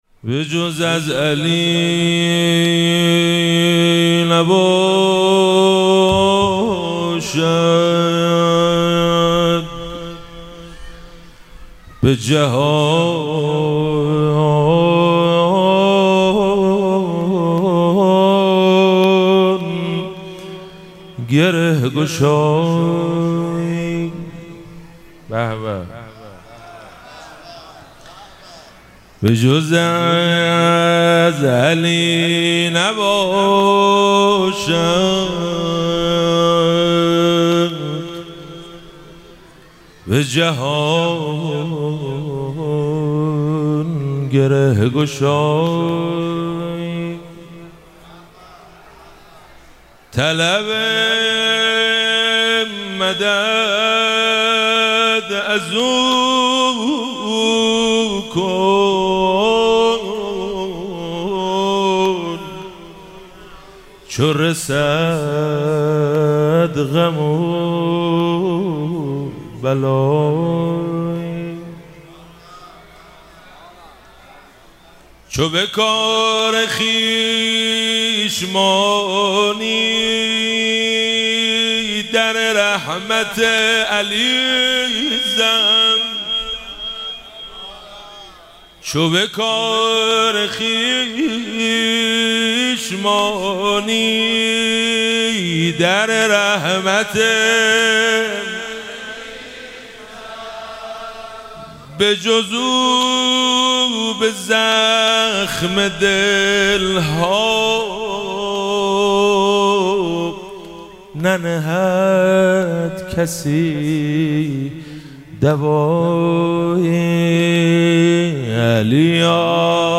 مراسم جشن شب سوم ویژه برنامه عید سعید غدیر خم 1444
مدح- به جز از علی نباشد به جهان گره گشایی